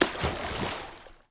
SPLASH_Small_07_mono.wav